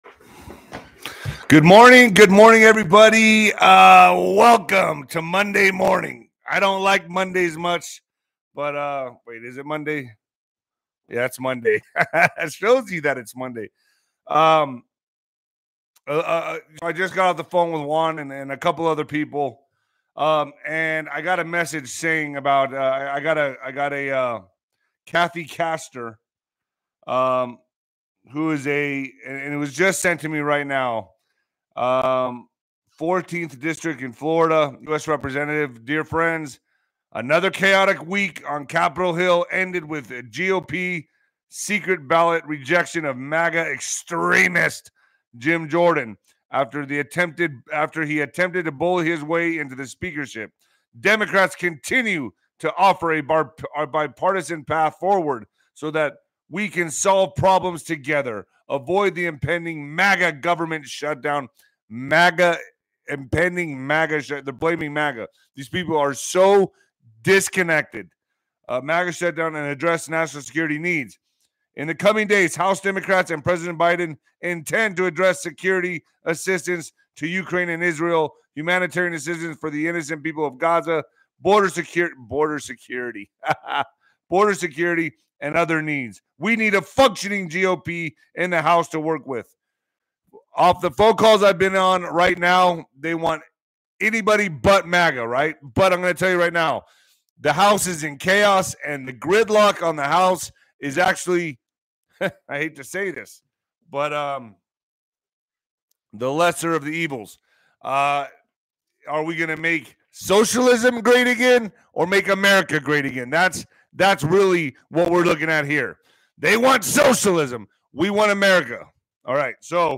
➡ The speaker discusses the recent political goings-on in the U.S, emphasizing the divide between supporters of MAGA and those against it. He also mentions future interviews and collaborations, as well as his experience with a revolutionary healing technology that supposedly enhances energy levels.